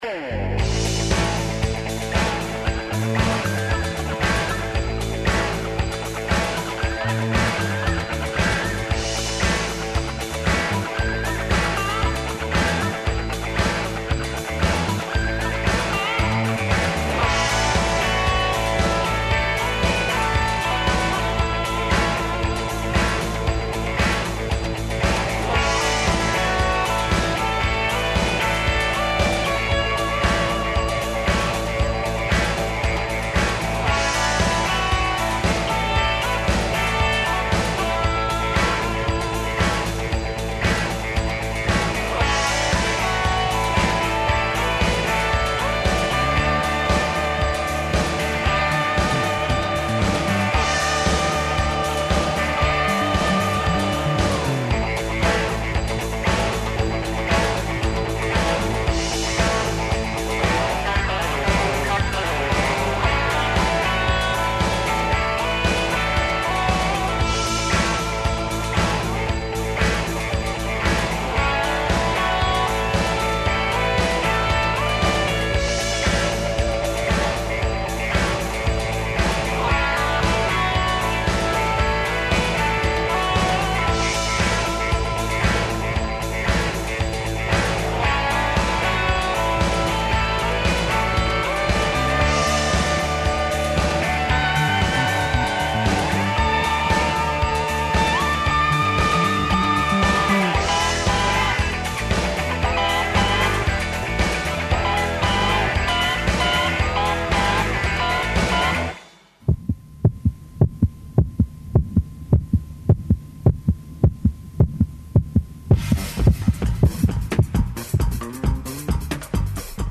Екипа Пулса је у Врњачкој Бањи где ове одржава Врњачки карневал, седми по реду, који ће трајати до недеље, 17. јула. Са највише пажње очекују се Дечја карневалска поворка, као и Међународна карневалска поворка у суботу, на којој ће учествовати карневалске групе из више земаља.